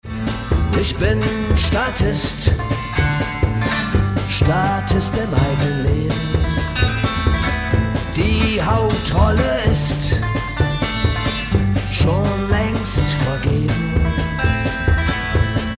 Schlagzeug, Bass, Gitarre, Gesang.